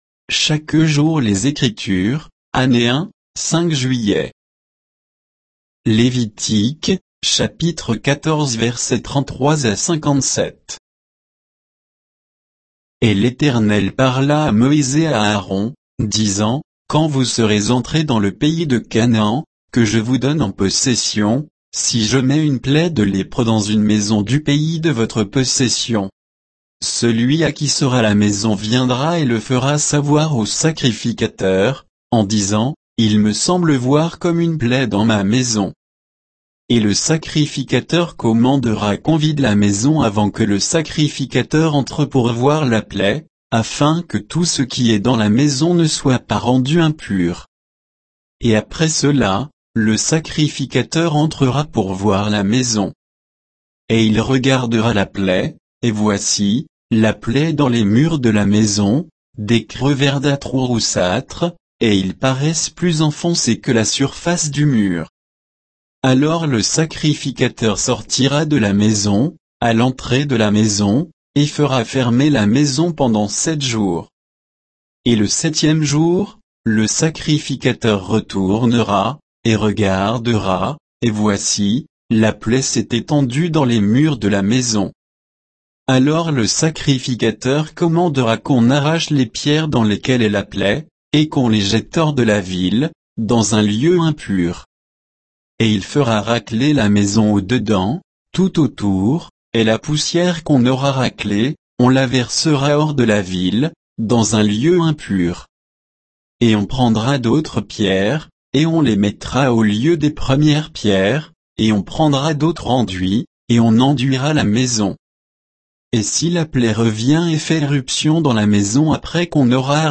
Méditation quoditienne de Chaque jour les Écritures sur Lévitique 14